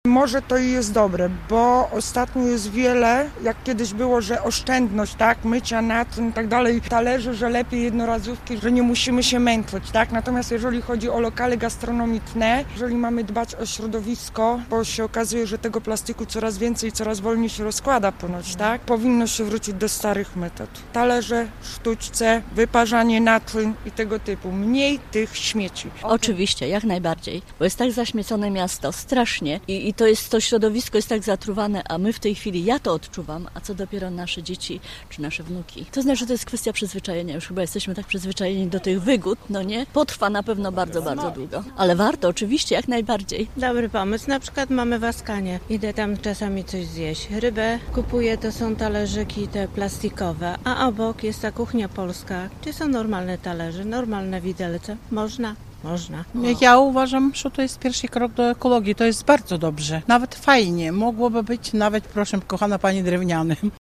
O to samo pytaliśmy też mieszkańców na ulicach: